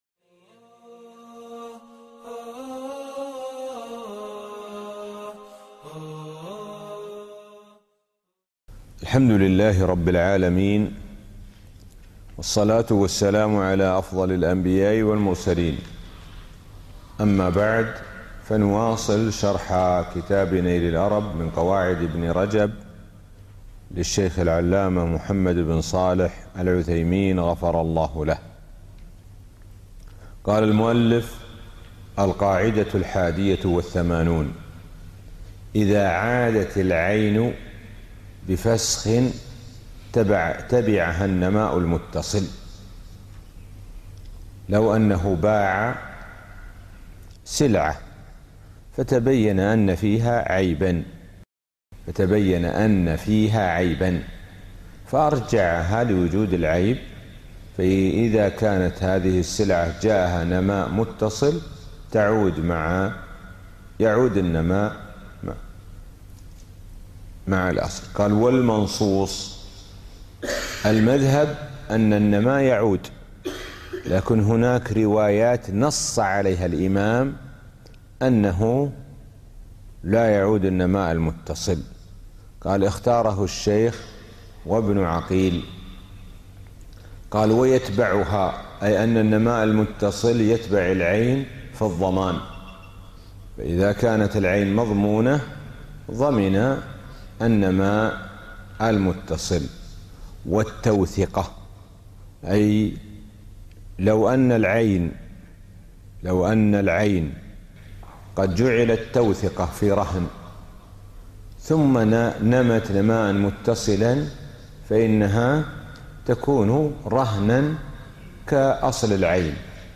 الموقع الرسمي لفضيلة الشيخ الدكتور سعد بن ناصر الشثرى | الدرس-10 من القاعدة [88 إلى 112]